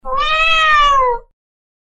猫の鳴き声05